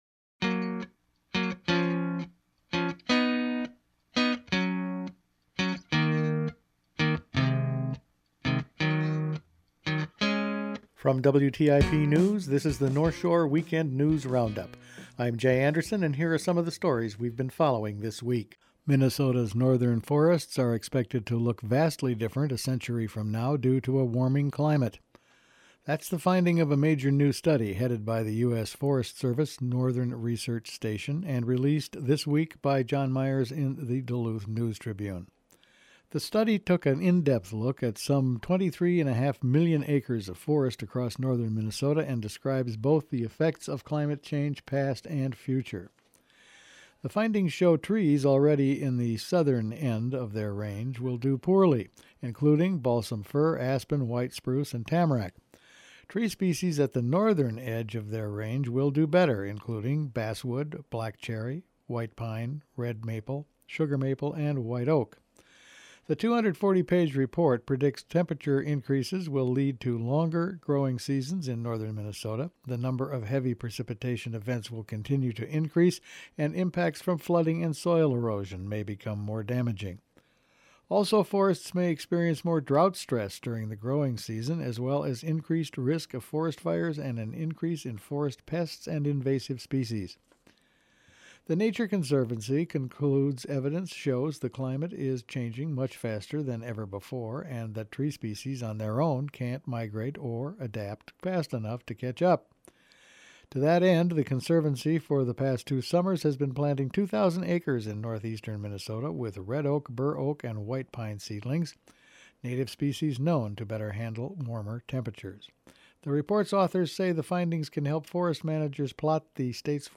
Weekend News Roundup for June 21